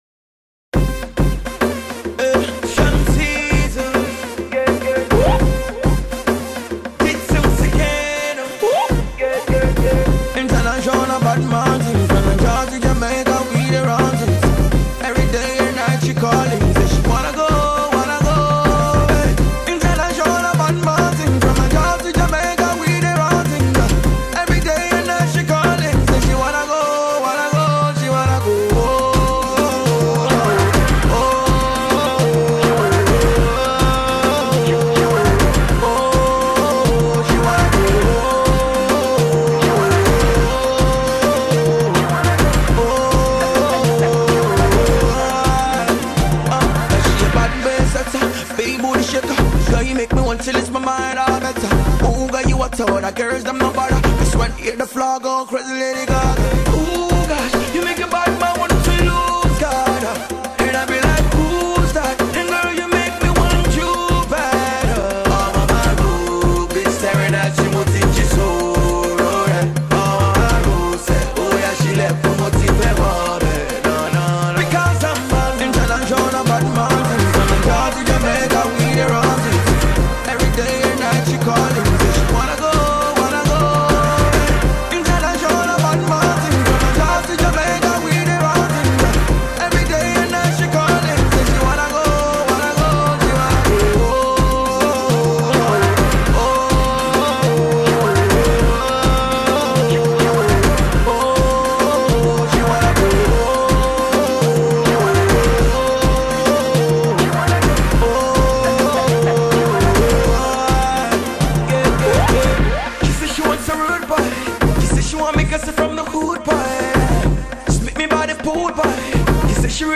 sounds a bit experimental